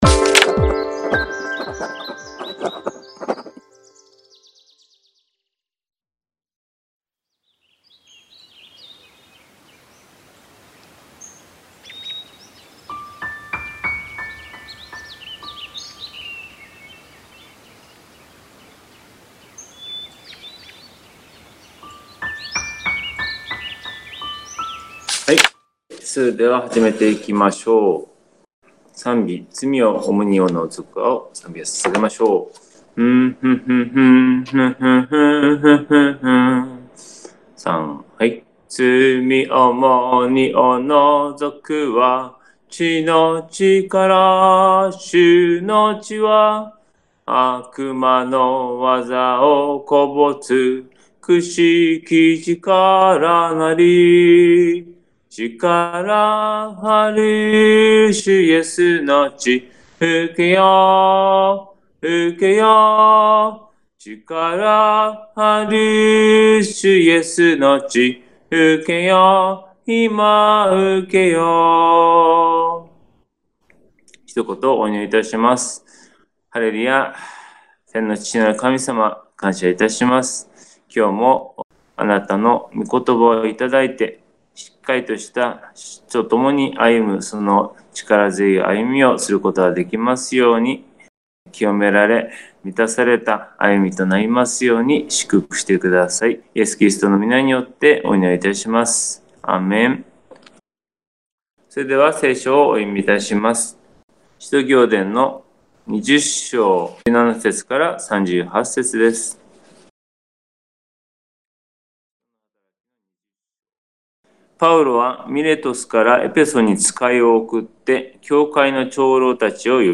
※映像と音声が一部乱れている部分がございます。